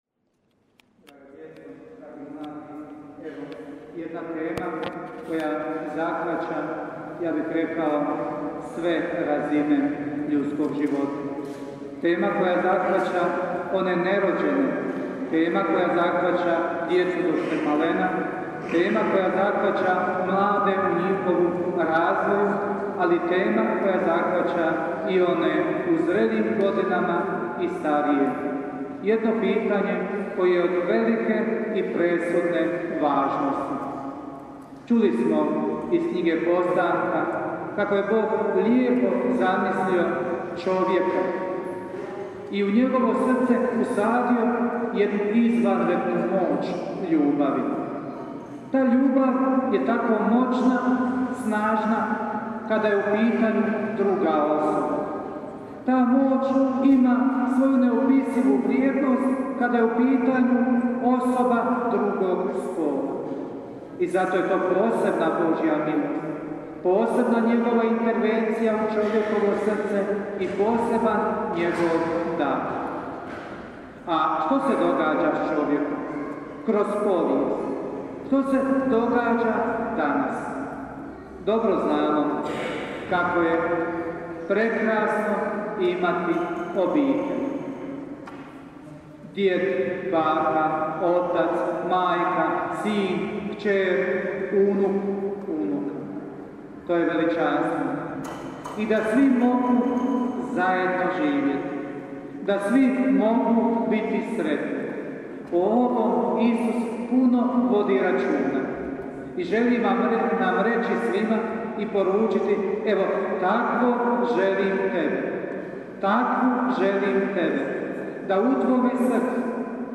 PROPOVJED: